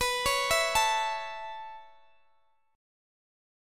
Listen to B7sus2sus4 strummed